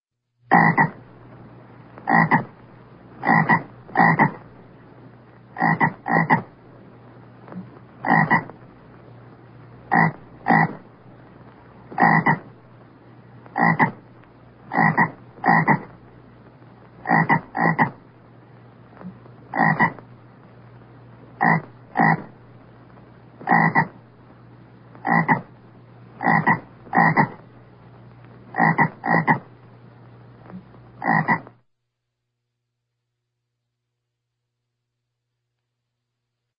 دانلود صدای قورباغه از ساعد نیوز با لینک مستقیم و کیفیت بالا
جلوه های صوتی